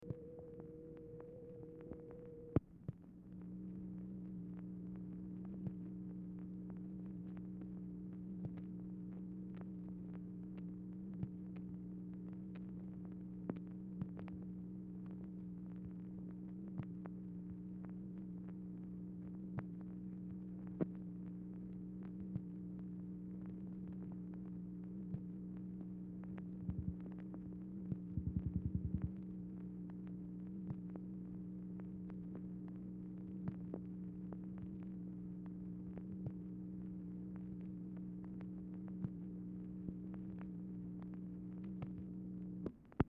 Telephone conversation # 1930, sound recording, MACHINE NOISE, 2/7/1964, time unknown | Discover LBJ
Format Dictation belt